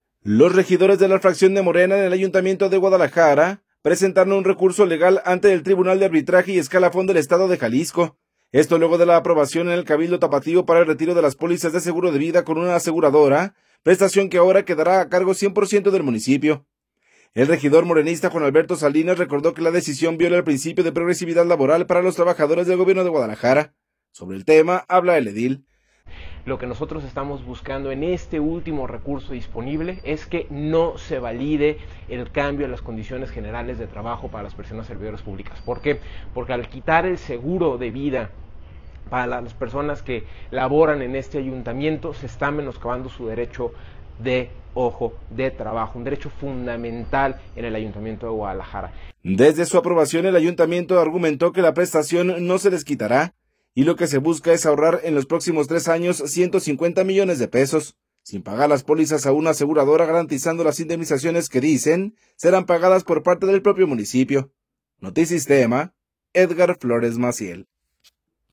El regidor morenista Juan Alberto Salinas, recordó que la decisión viola el principio de progresividad laboral para los trabajadores del gobierno de Guadalajara. Sobre el tema habla el edil.